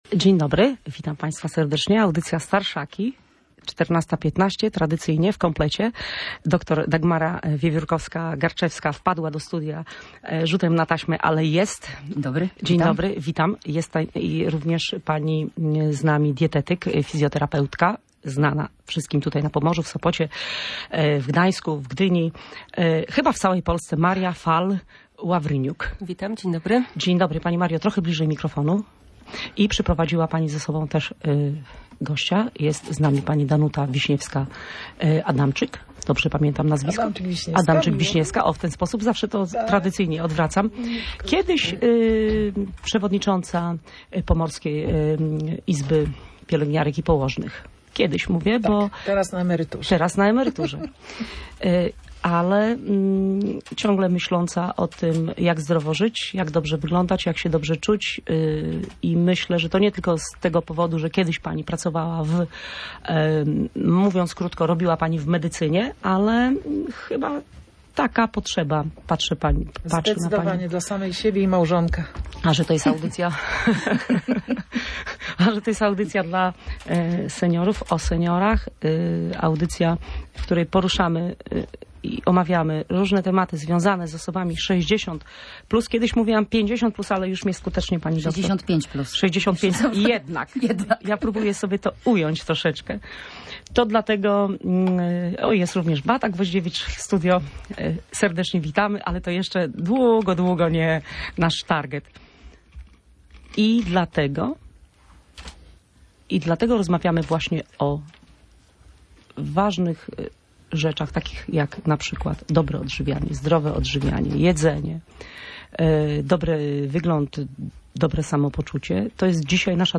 rozmawia dziś z geriatrą, dietetyczką i seniorką.